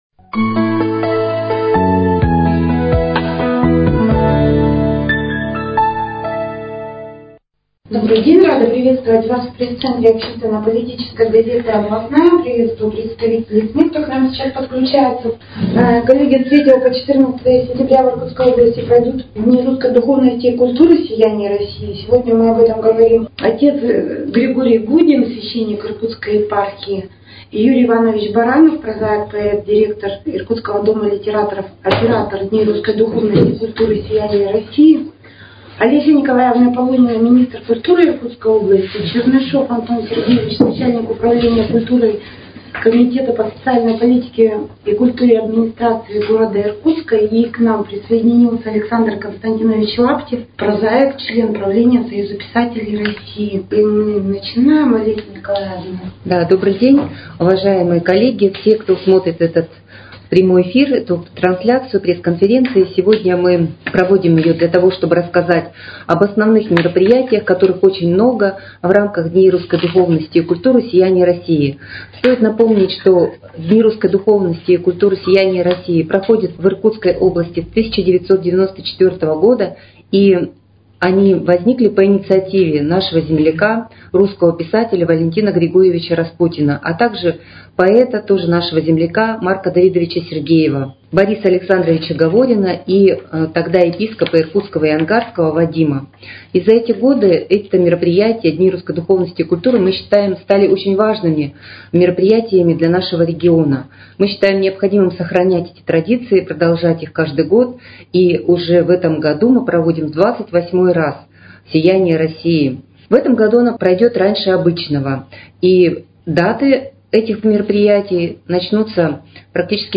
Предлагаем Вашему вниманию запись пресс-конференции приуроченной этому событию.